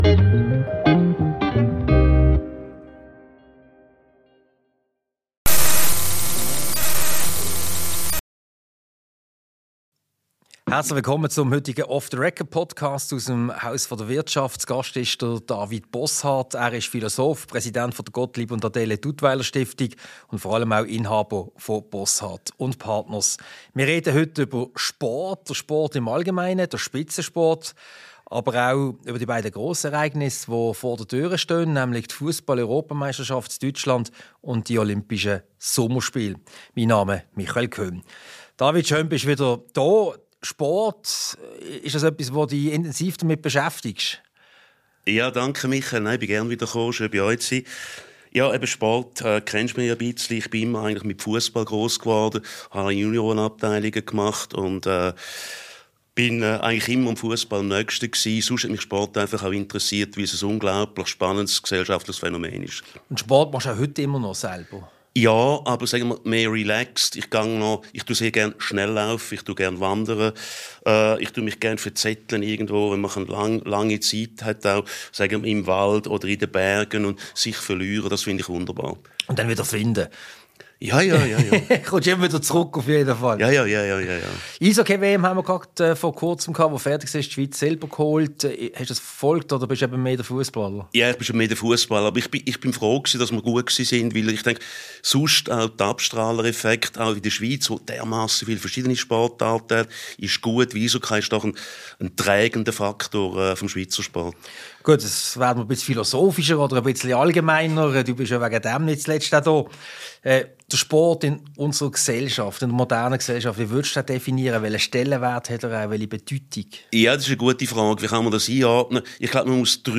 Ein Gespräch über das Thema Sport: Den Sport im Allgemeinen, den Spitzensport und dabei natürlich auch über die Fussball-Europameisterschaft in Deutschland und über die Olym...